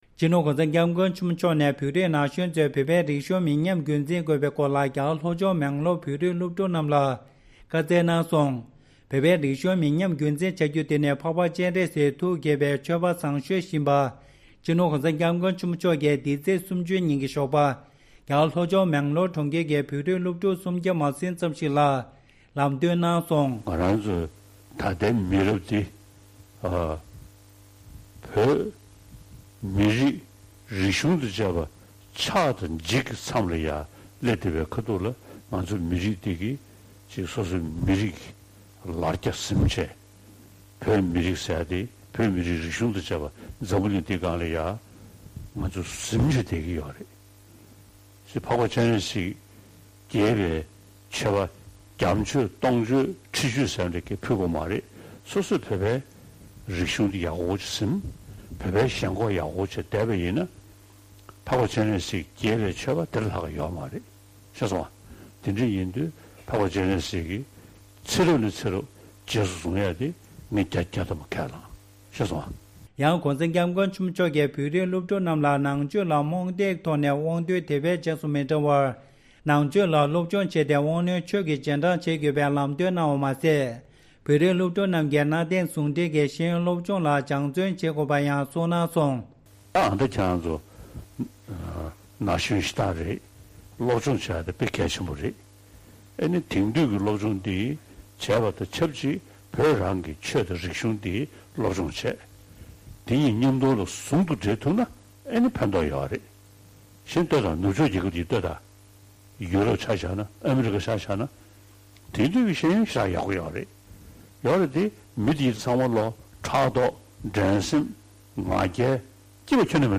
ས་གནས་ནས་བཏང་བའི་གནས་ཚུལ།
སྒྲ་ལྡན་གསར་འགྱུར། སྒྲ་ཕབ་ལེན།